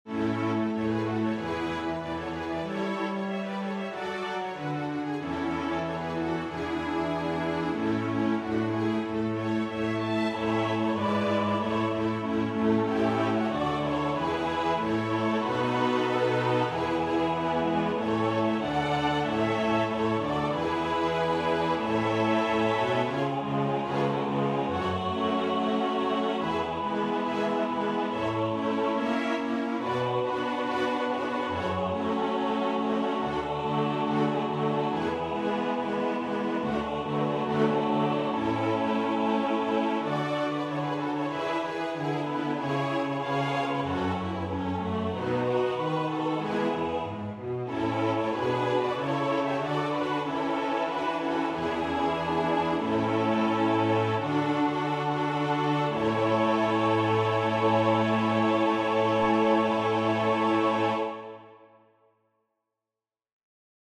• Catégorie : Chants d’Acclamations.